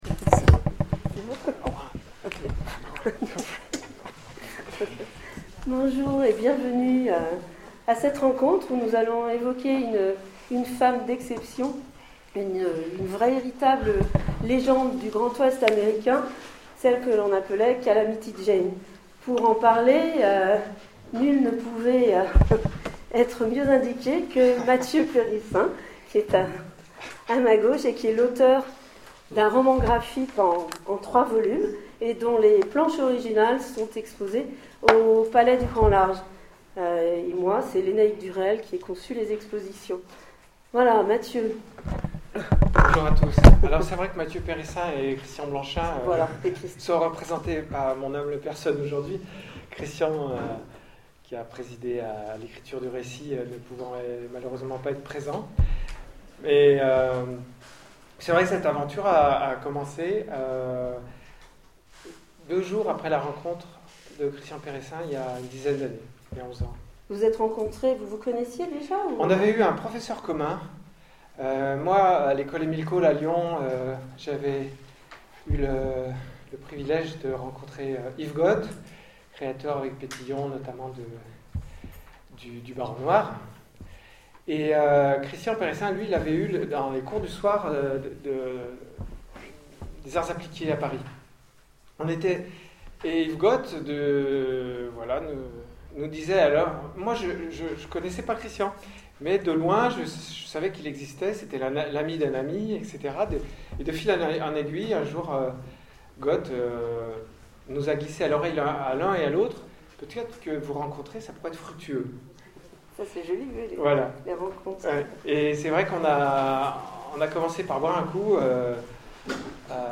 Etonnants Voyageurs 2013 : Conférence La véritable histoire de Martha Jane Cannary, alias Calamity Jane